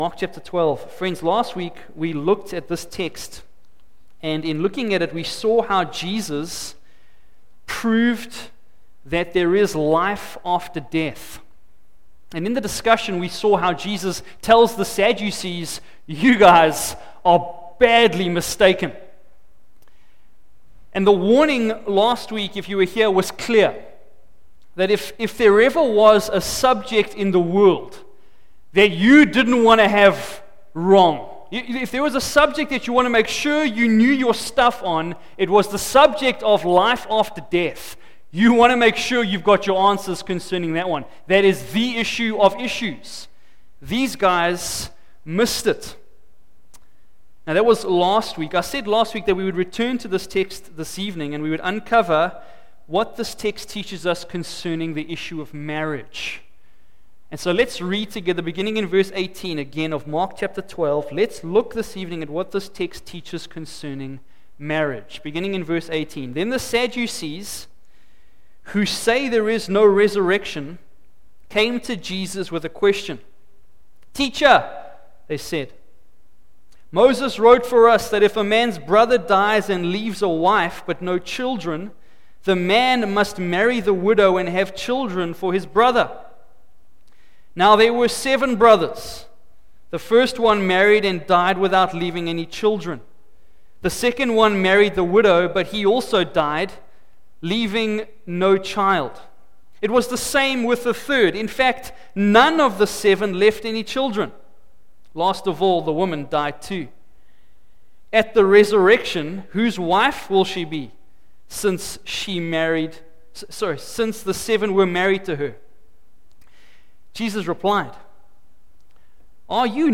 Mark 12 : 18-27 “Badly mistaken” Part 2 Share this... Facebook Twitter email Posted in Evening Service